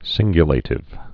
(sĭnggyə-lātĭv, -lə-tĭv)